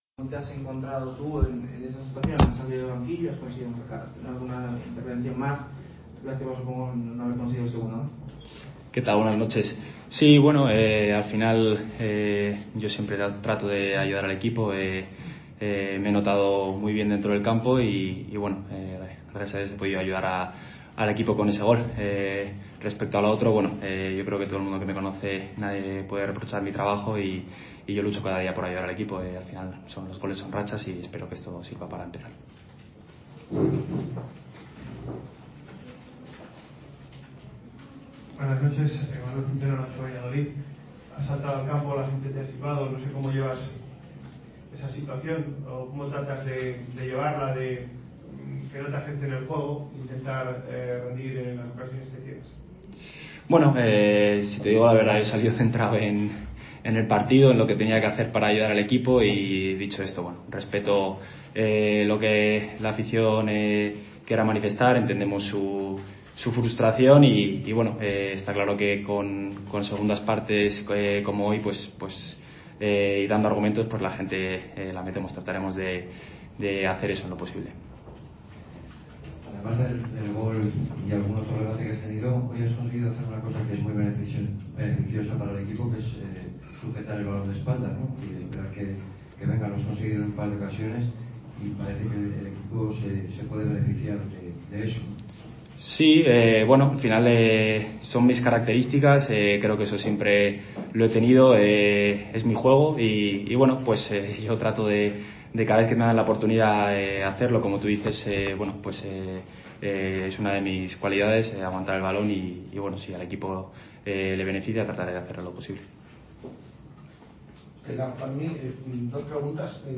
Ruedas de prensa
aquí la rueda de prensa completa